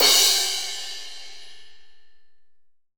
Index of /90_sSampleCDs/AKAI S6000 CD-ROM - Volume 3/Crash_Cymbal2/SHORT_DECAY_CYMBAL